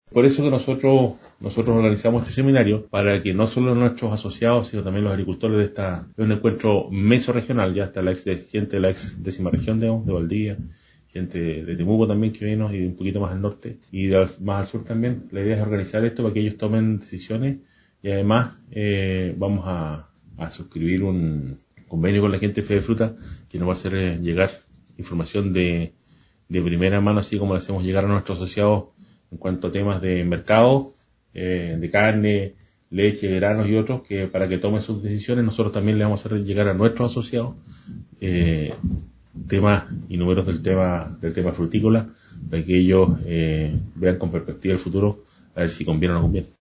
La fruticultura se reunió en el recinto SAGO en el encuentro regional de FEDEFRUTA.